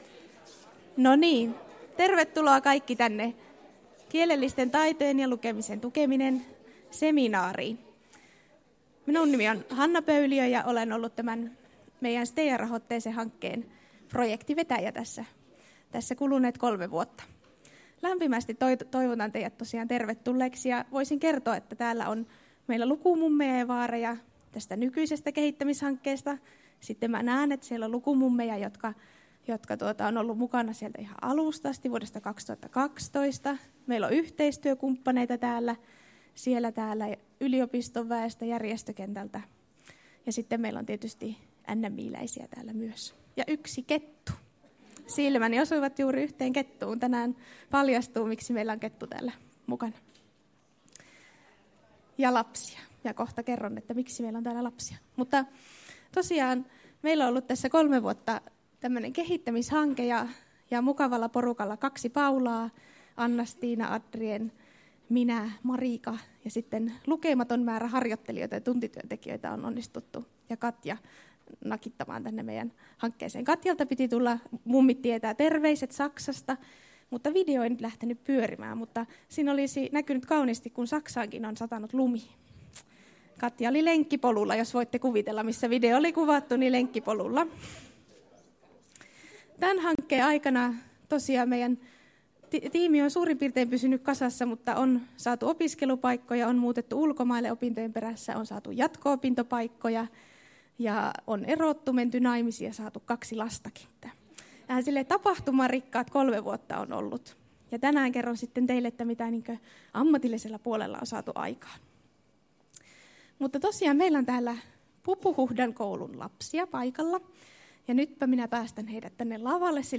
Päätösseminaari Maahanmuuttajien kielellisten taitojen ja lukemisen tukeminen